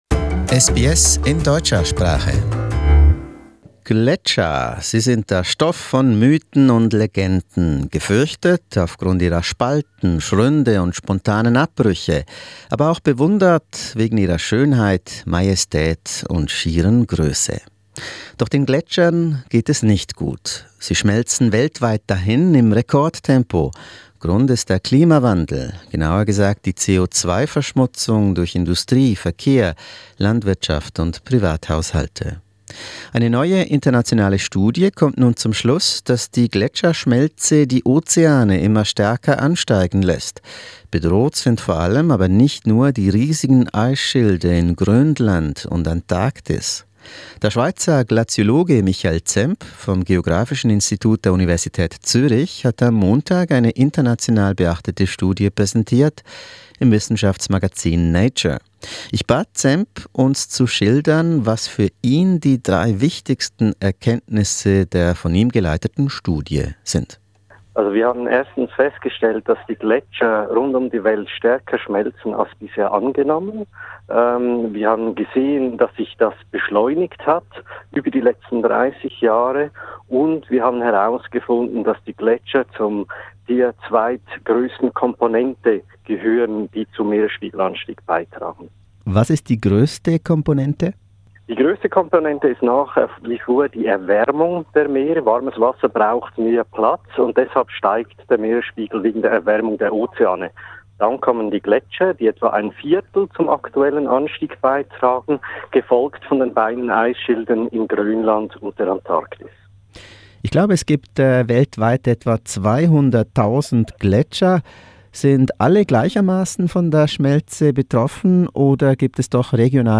Eine neue internationale Studie zeigt, dass die riesigen Eisschilder und Gletscher in Grönland, Antarktis und weltweit schneller schmelzen als bisher angenommen. Seit 1961 ist eine Masse verloren gegangen, die einem 30 Meter dicken Eiswürfel von der Grösse Deutschlands entspricht. Ein Interview